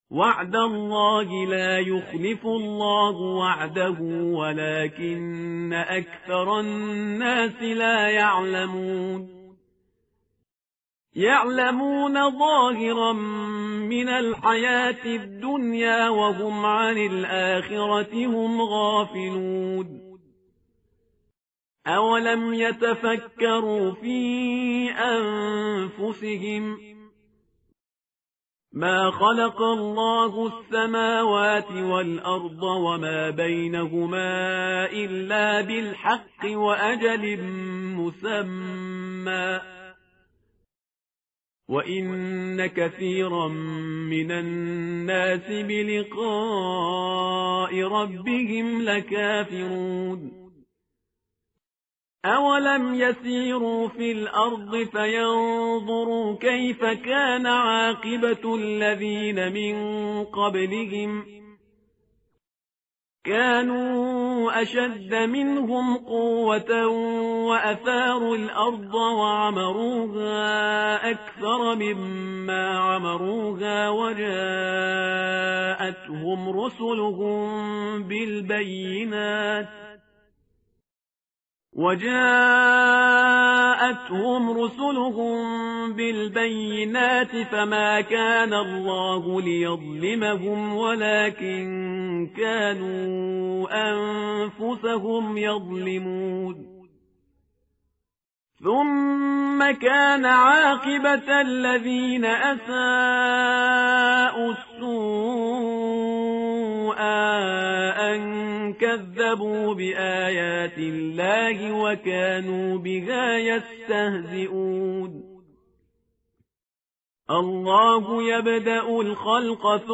tartil_parhizgar_page_405.mp3